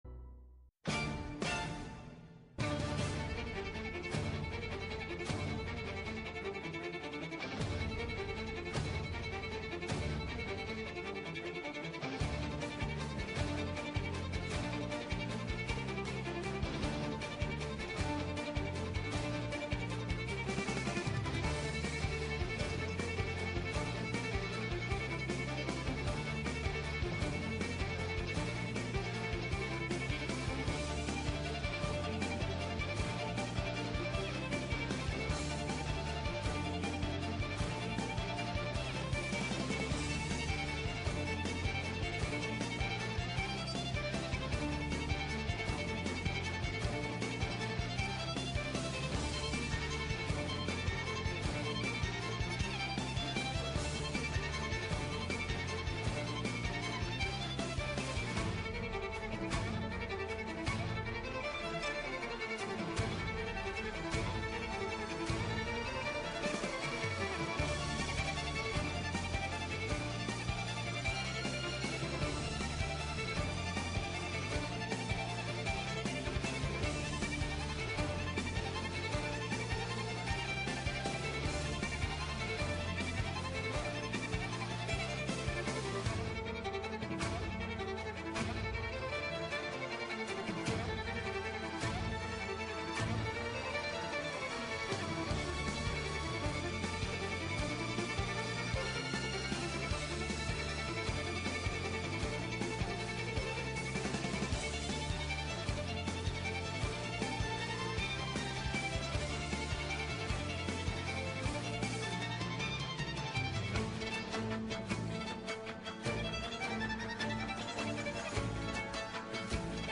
Πολύ ωραία ηλεκτρικά blues rock
Live στο Studio